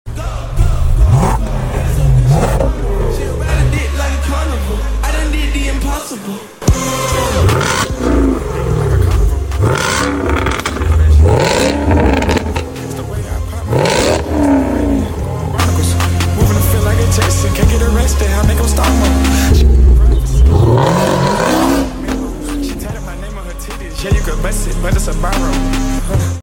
The best modification for the Land Rover Defender V8… we installed a performance DOWNPIPE for CAT and OPF delete. This thing sounds like a MONSTER!